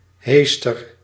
Ääntäminen
Ääntäminen US : IPA : [ʃɻʌb]